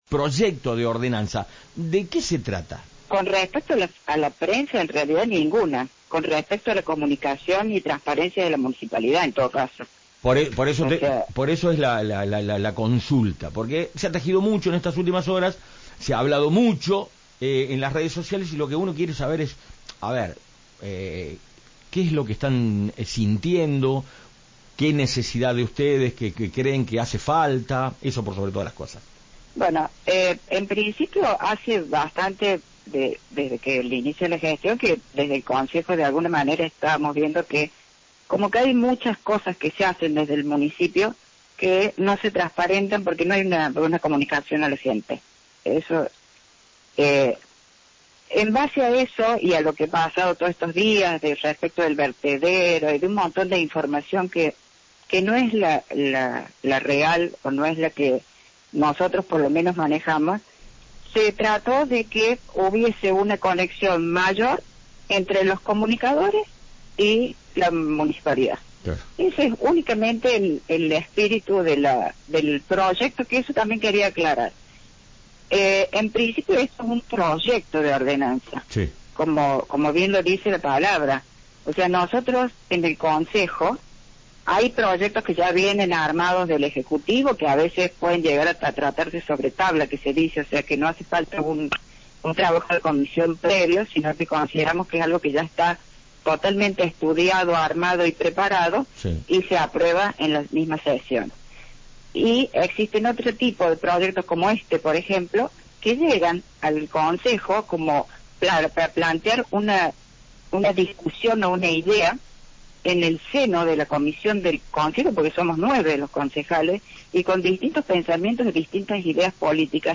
Ante los señalamientos, la concejal Mónica Sormani dialogó con Cadena Centro FM y rechazó que la iniciativa busque limitar la labor de la prensa.